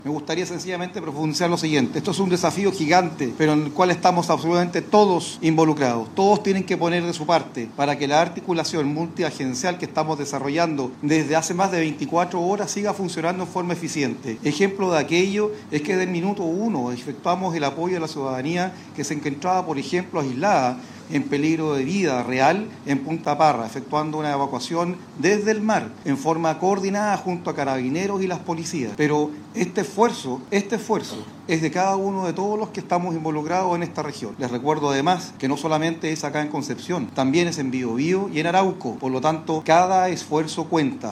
Por su parte, el almirante Acevedo, Jefe de la Defensa Nacional en el Biobío, señaló que frente a la catástrofe esto ha sido un desafío gigante.